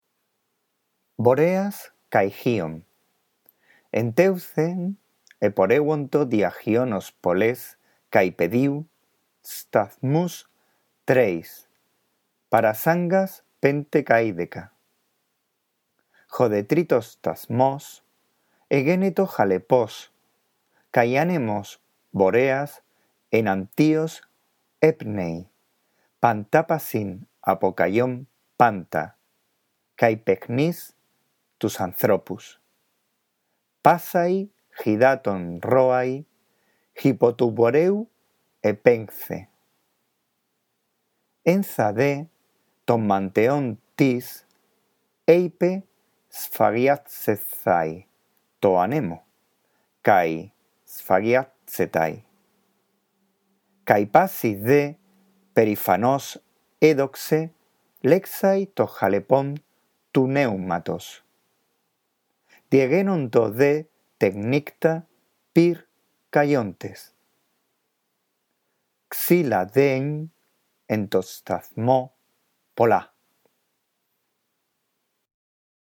2.ª actividad. ἡ ἀνάγνωσις, la lectura
La audición de este archivo te ayudará en la práctica de la lectura del griego: